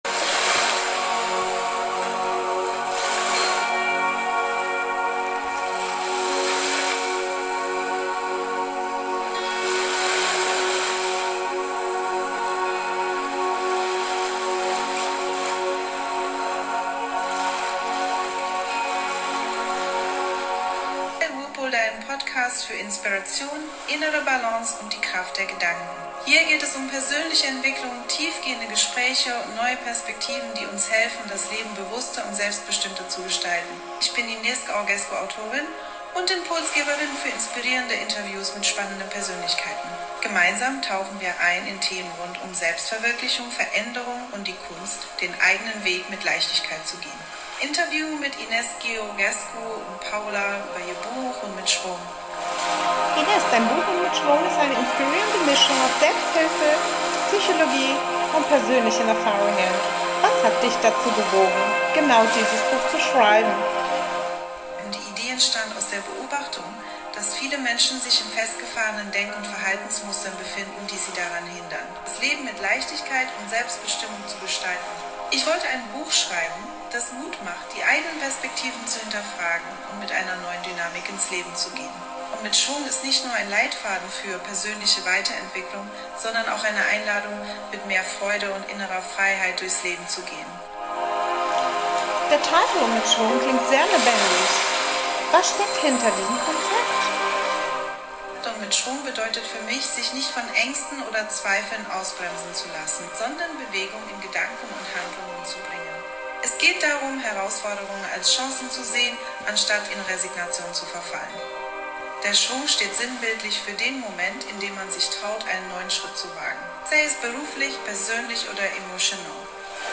In inspirierenden Interviews mit spannenden Persönlichkeiten erkunden wir Themen wie Selbstverwirklichung, Veränderung und Resilienz.